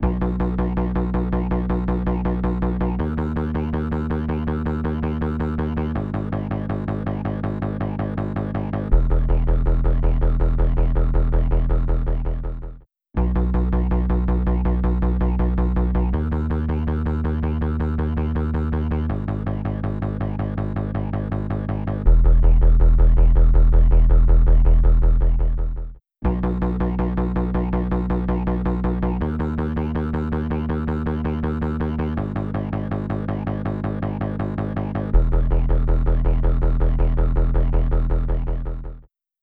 低周波数の引き締めによる、より洗練された音のバランスの実現